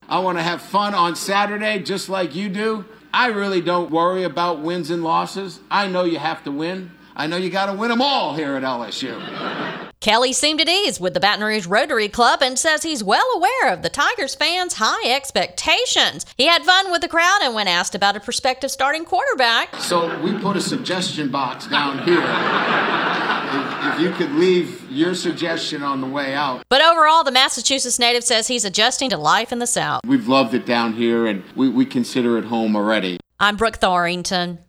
LSU head football coach Brian Kelly was able to show a bit of his personality when he addressed the Baton Rouge Rotary Club Wednesday. The Massachusetts native who’s spent the majority of his 32 years as a head coach in the Midwest said it’s been an easy adjustment for him and his family in the south.